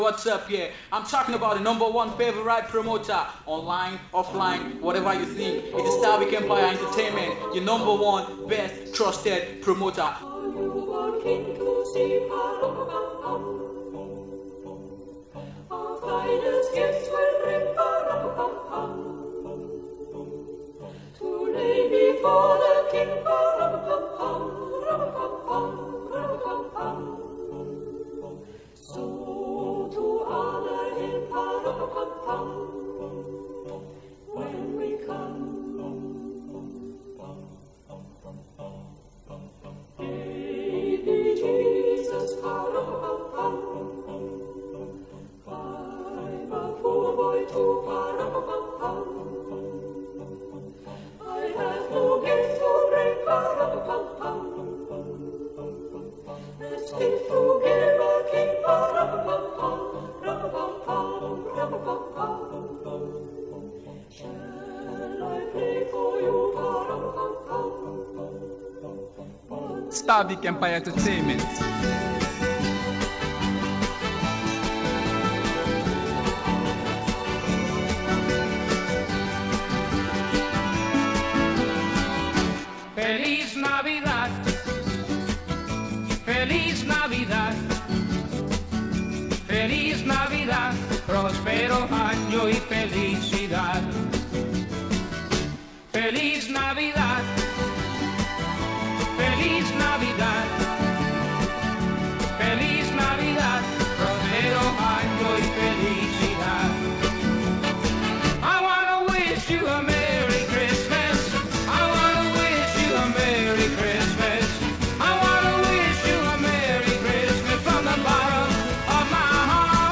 A powerful collection gospel sound, by various Gospel singer